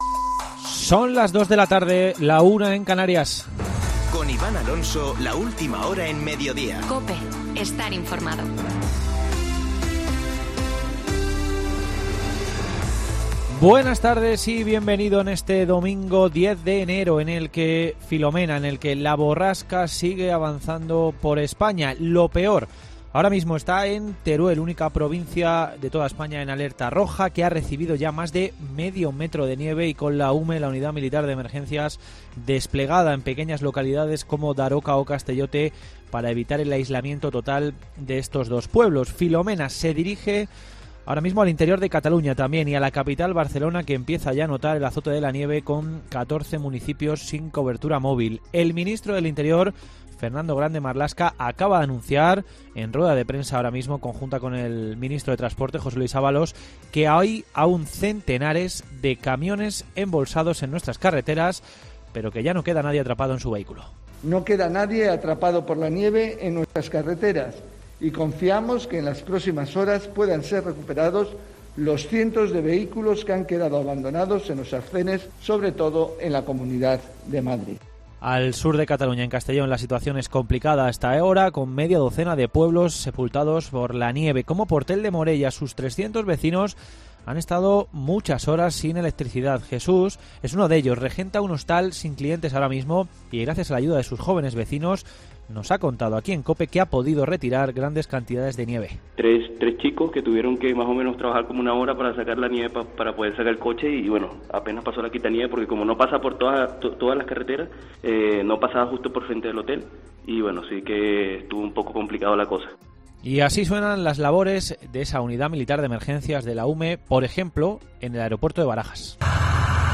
Boletín de noticias COPE del 10 de enero de 2021 a las 14.00 horas